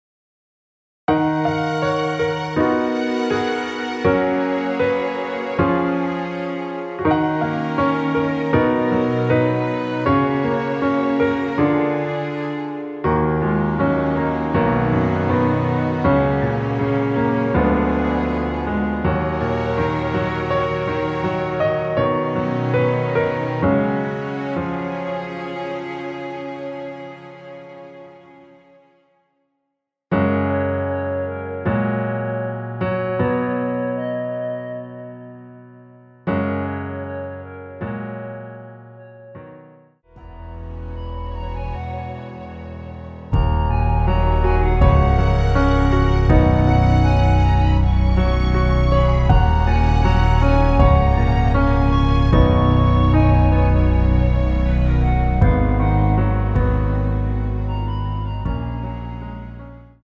원키에서(-2)내린 멜로디 포함된 MR입니다.
F#
앞부분30초, 뒷부분30초씩 편집해서 올려 드리고 있습니다.
중간에 음이 끈어지고 다시 나오는 이유는